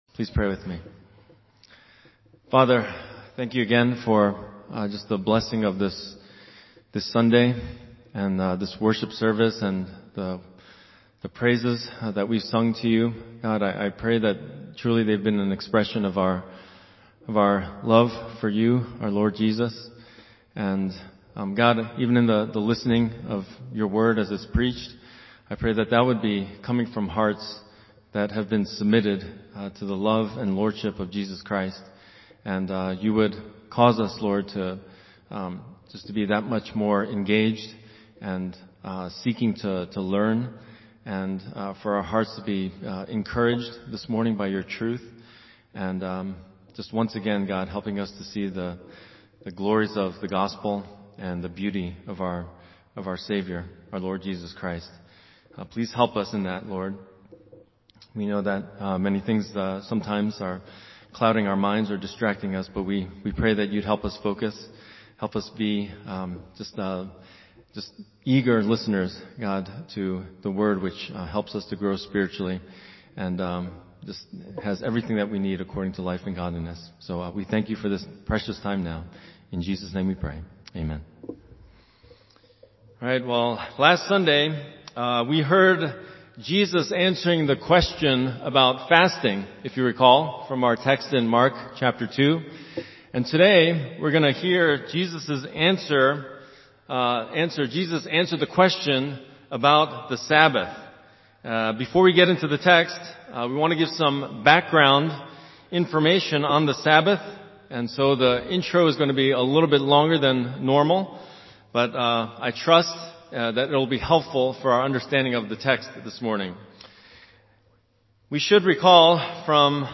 Sermon Theme/Outline: Jesus teaches on and claims to be the authority of the Sabbath